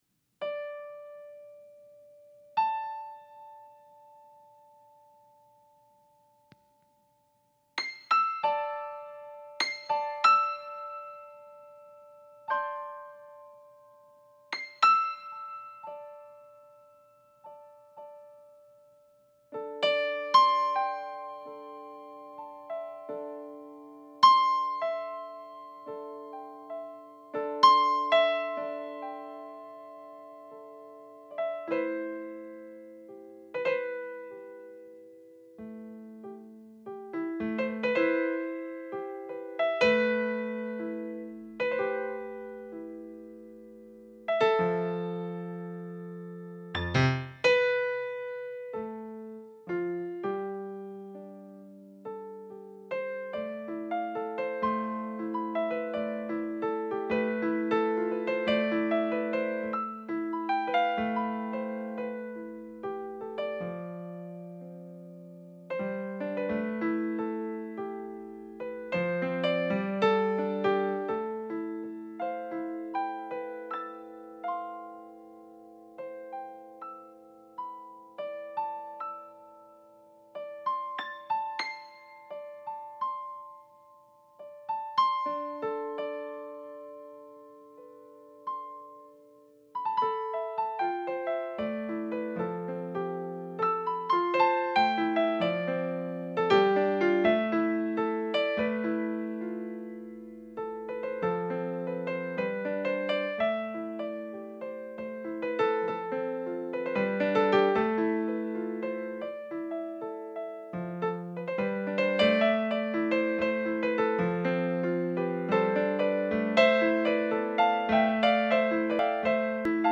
Mes exercices de réchauffements préférés.
Presque pas de notes noires.